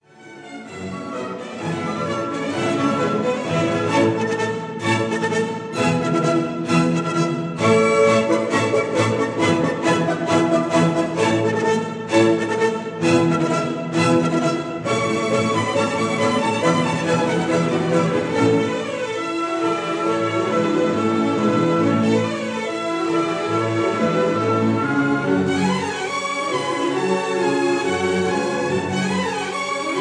Allegro con brioe